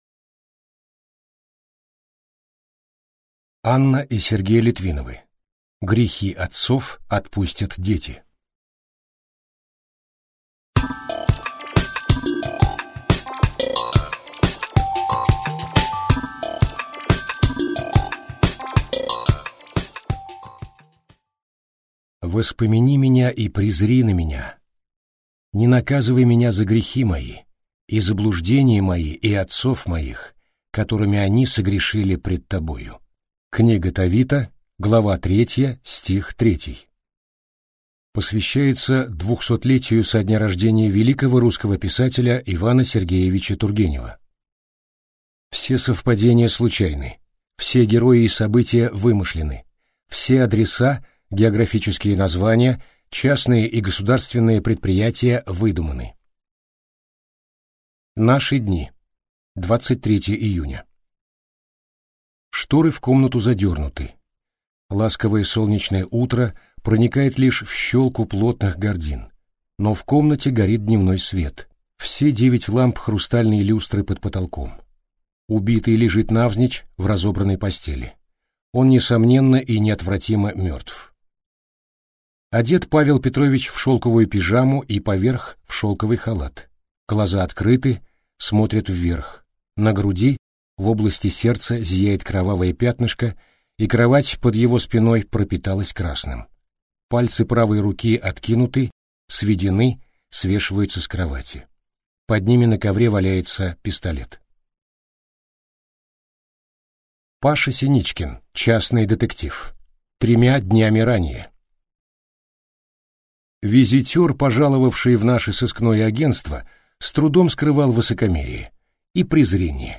Аудиокнига Грехи отцов отпустят дети | Библиотека аудиокниг